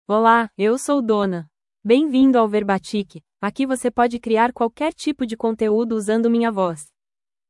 FemalePortuguese (Brazil)
Donna is a female AI voice for Portuguese (Brazil).
Voice sample
Female
Donna delivers clear pronunciation with authentic Brazil Portuguese intonation, making your content sound professionally produced.